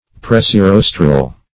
Search Result for " pressirostral" : The Collaborative International Dictionary of English v.0.48: Pressirostral \Pres`si*ros"tral\, a. (Zool.)